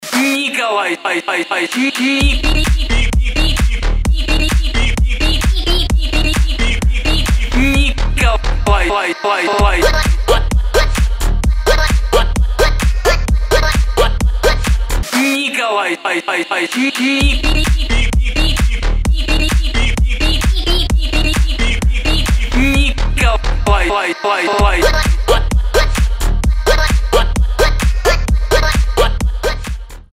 • Качество: 320, Stereo
громкие
remix
веселые
club